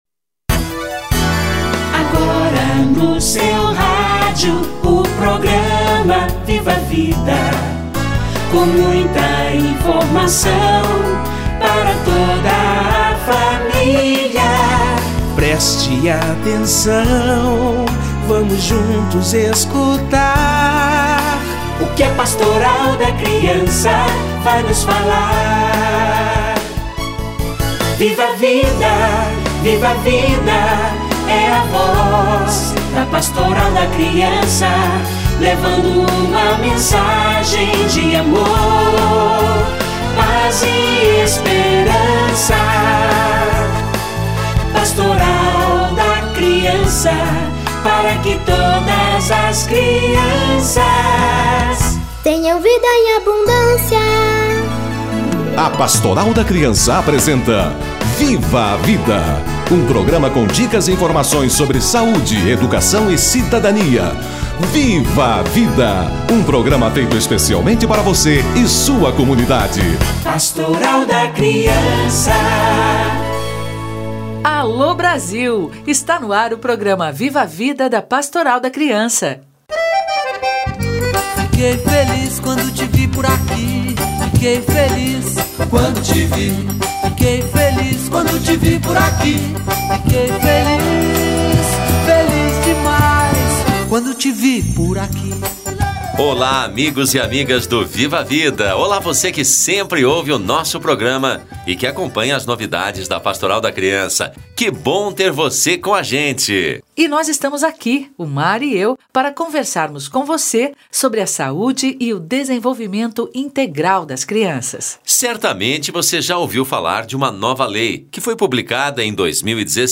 Marco Legal da Primeira Infância - Entrevista